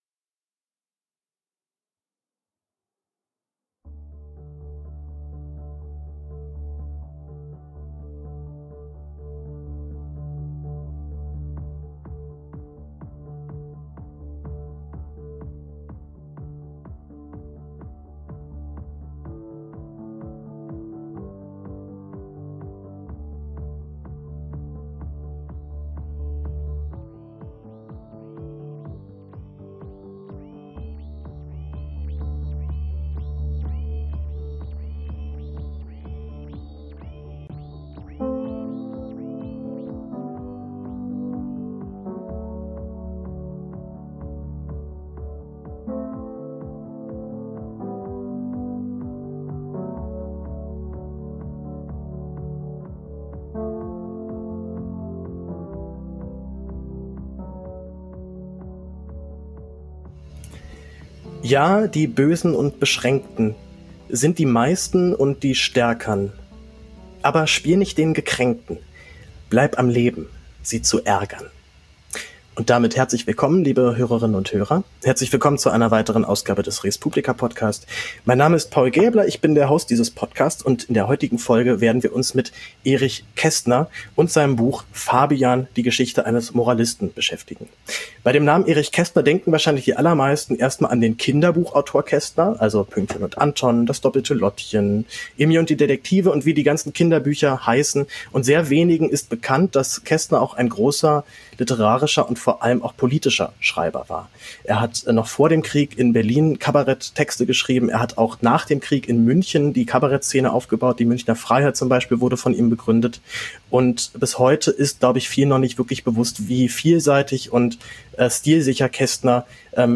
Ich spreche mit dem Literaturwissenschaftler